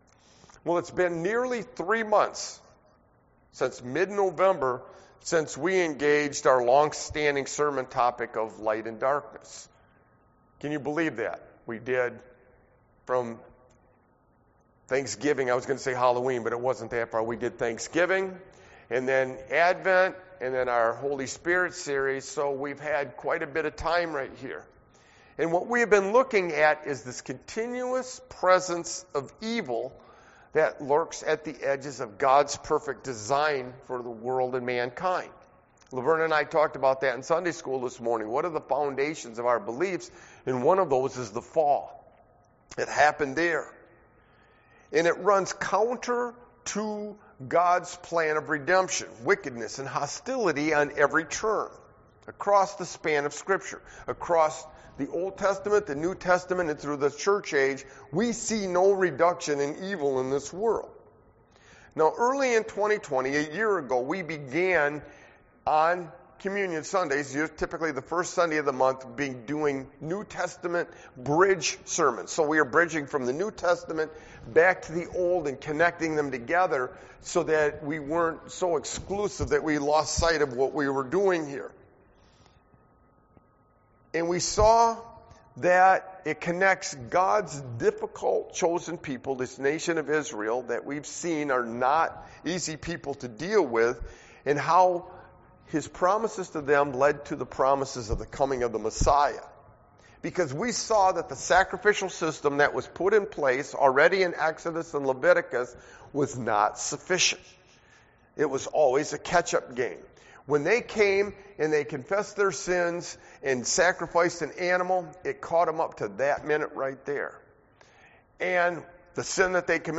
Sermon-Looking-Forward-from-an-OT-View-21421.mp3